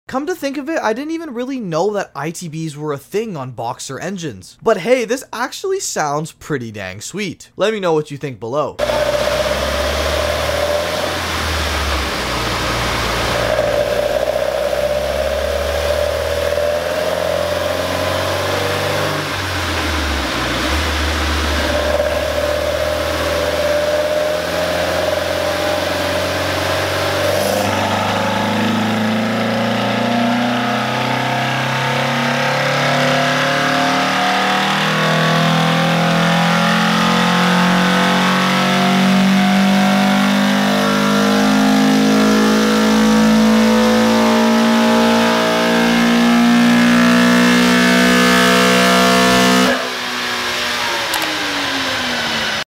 SUBARU BRZ, PURE BOXER SOUND sound effects free download
PURE BOXER SOUND Mp3 Sound Effect SUBARU BRZ, PURE BOXER SOUND W/ITBS!